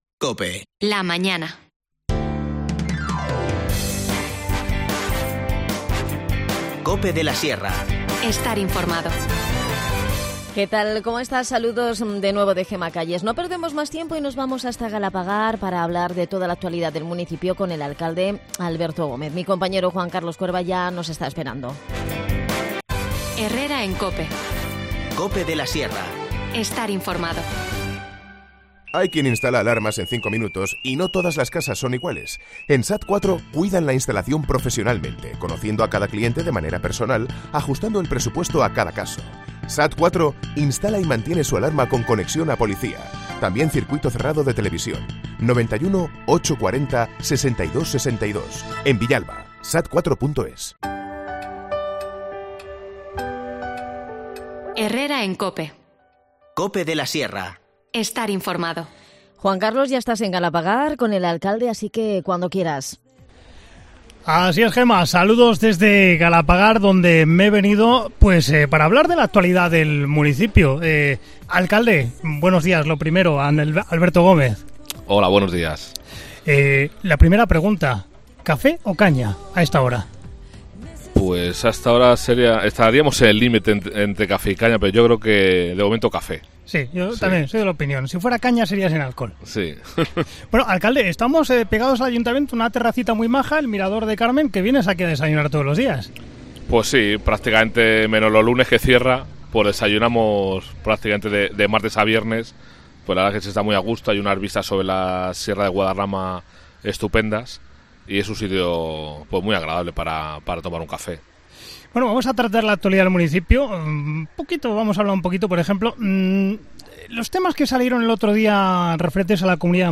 Abordamos la actualidad de Galapagar con Alberto Gómez, alcalde de la localidad, en nuestro “Café o caña”.